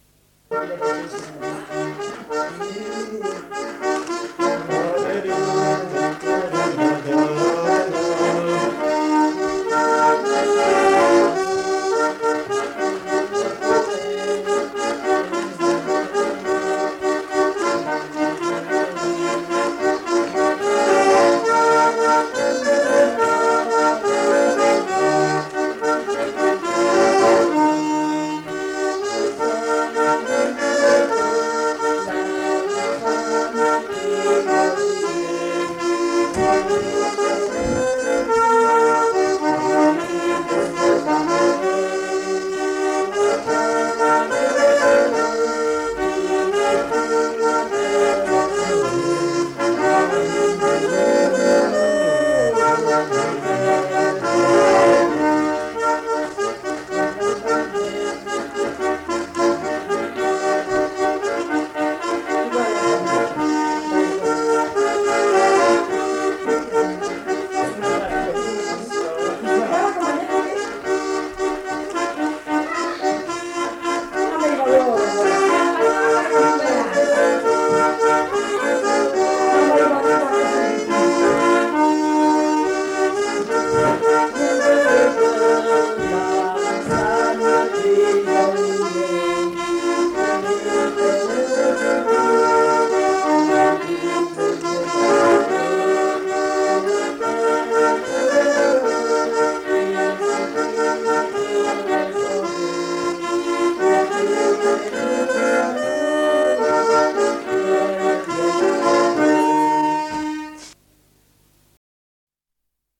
Lieu : Mas-Cabardès
Genre : morceau instrumental
Instrument de musique : accordéon diatonique
Danse : valse
Notes consultables : Le joueur d'accordéon n'est pas identifié.